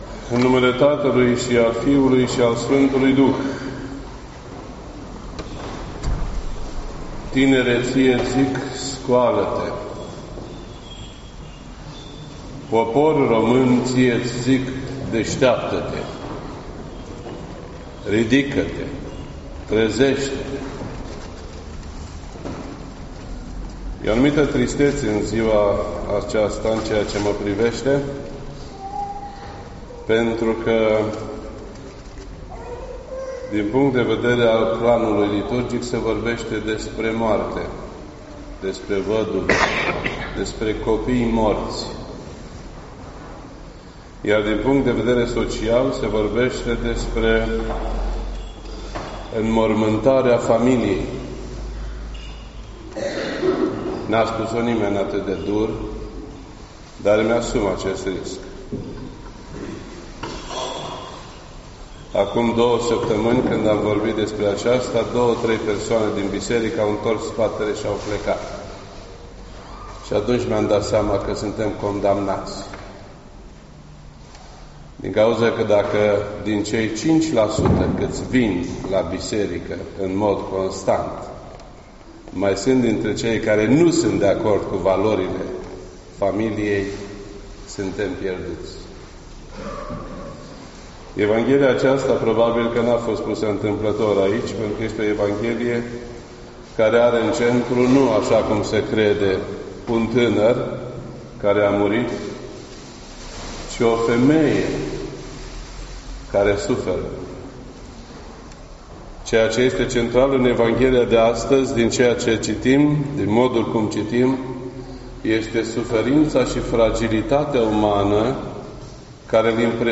This entry was posted on Sunday, October 7th, 2018 at 12:49 PM and is filed under Predici ortodoxe in format audio.